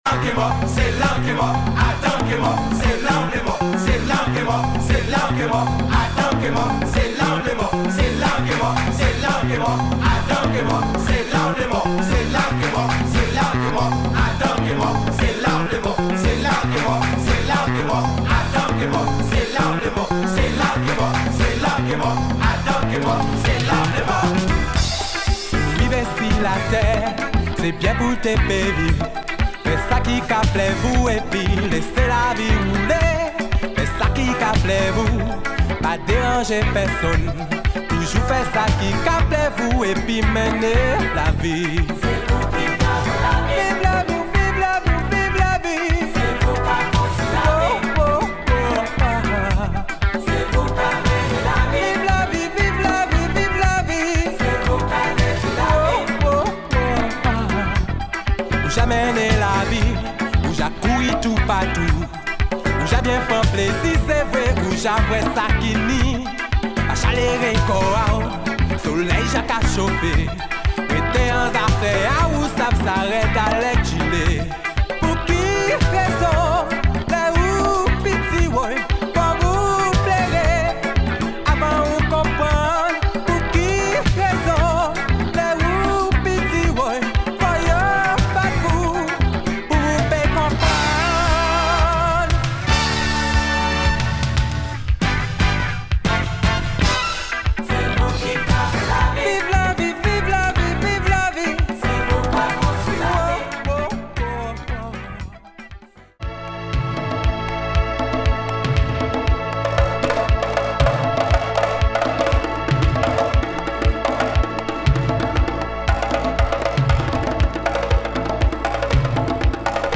funky
synths
bass
guitar